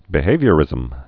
(bĭ-hāvyə-rĭzəm)